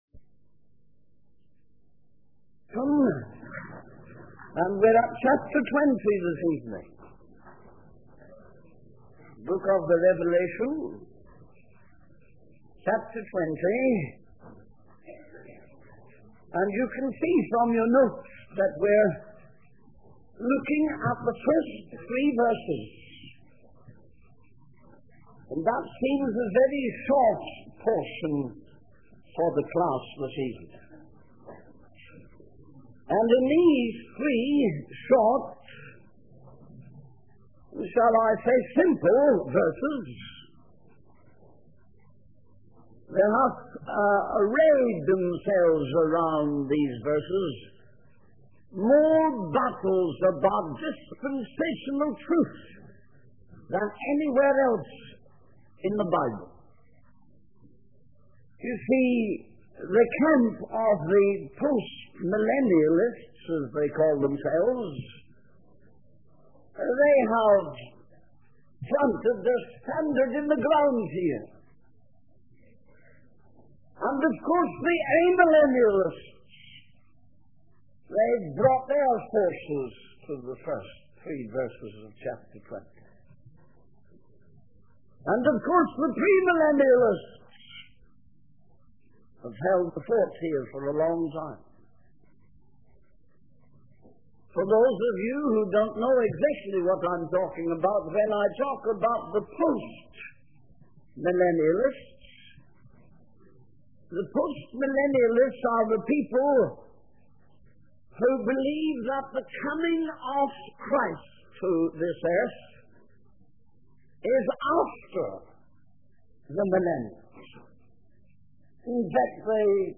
In this sermon, the preacher begins by quoting a passage from the Bible about the Spirit of the Lord being upon him to preach good tidings to the meek. He then talks about the key of the bottomless pit and the opening of the prison for those who are bound.